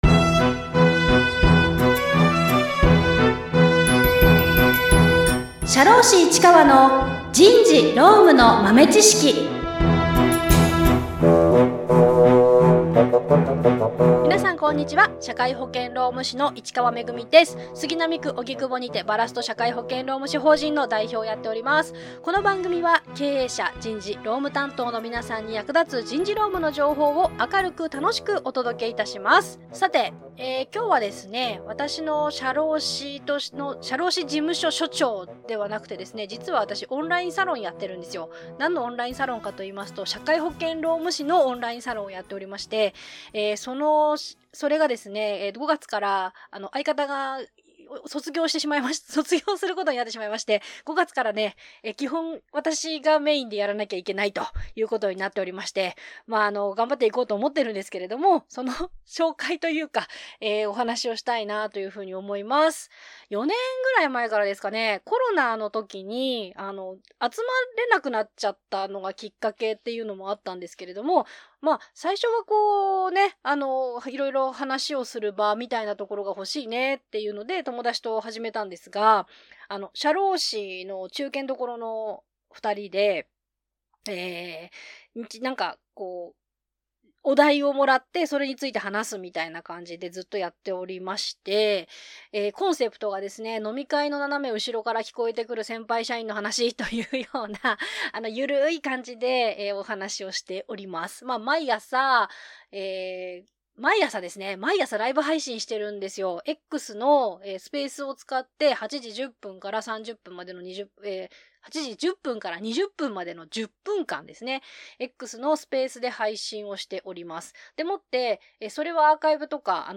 経営者、人事部、すべての上司に向けて、社会保険労務士が、「働き方改革」「社会保険」「労使トラブル」など最近の人事労務の話題をおしゃべりしています。